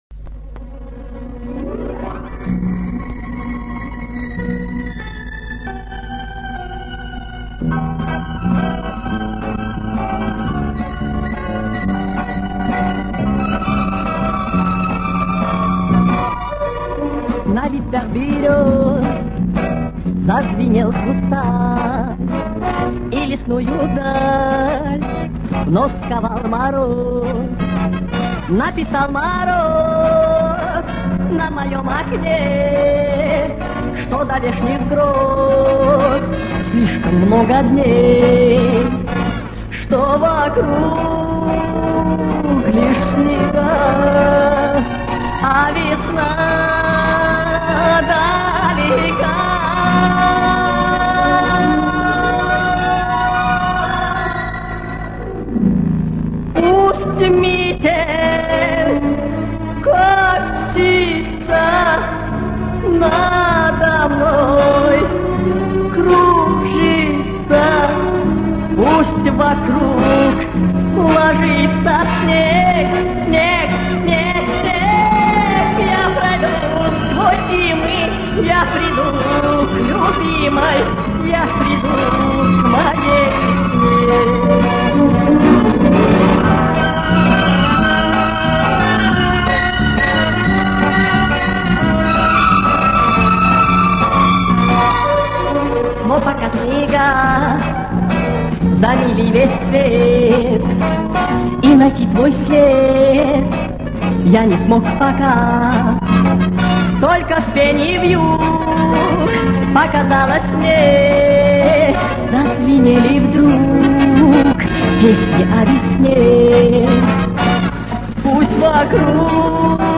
За качество извините